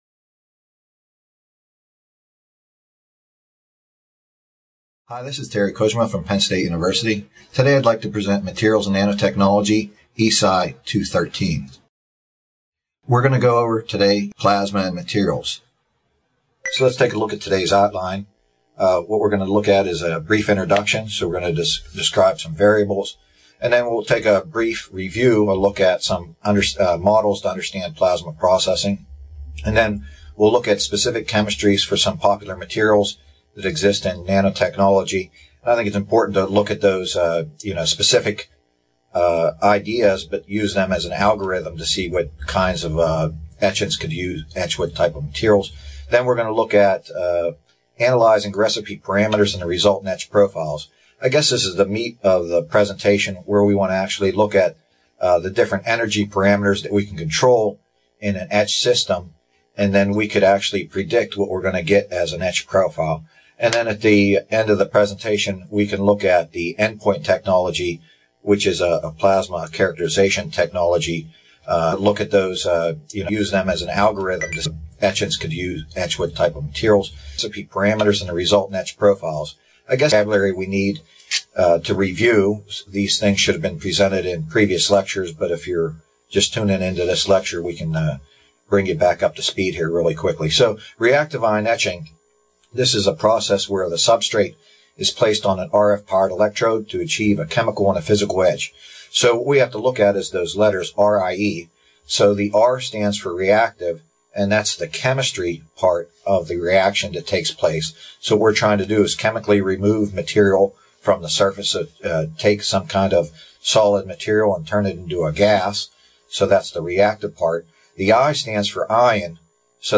This video, published by the Nanotechnology Applications and Career Knowledge Support (NACK) Center at Pennsylvania State University, is part one of a two-part lecture on plasma and nanotechnology materials.